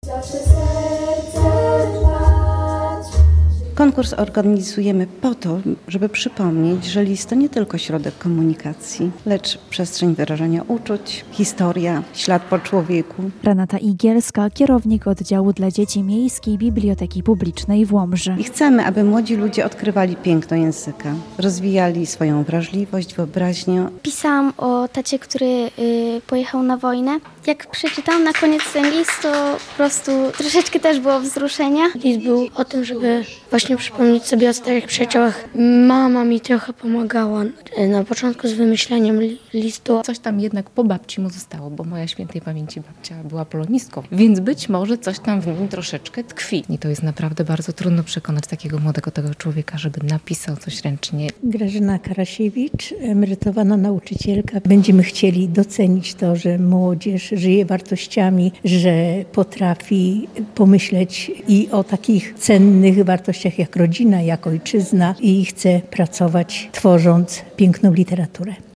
We wtorek (18.11) w Hali Kultury uroczyście podsumowano literacką rywalizację, a zwycięzcom wręczono nagrody.
Więcej w naszej relacji: